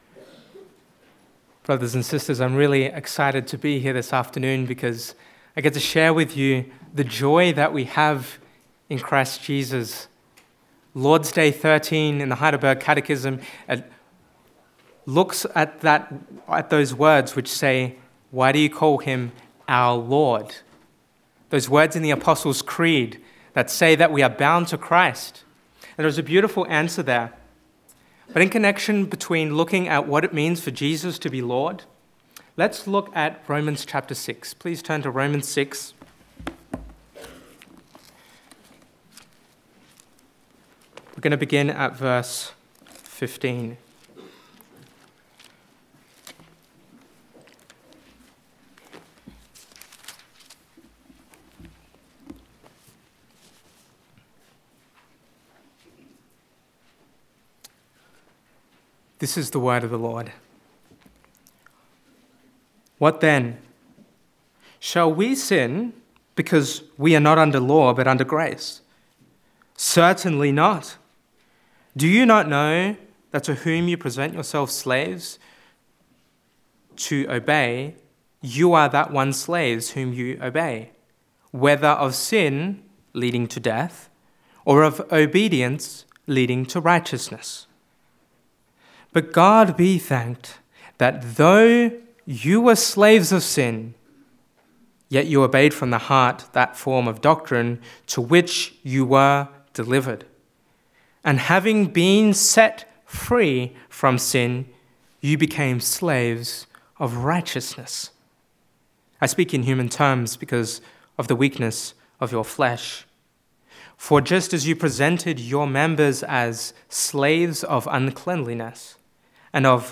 Passage: Romans 6:15-23 Service Type: Sunday Afternoon « Who Shall be the Promised Seed?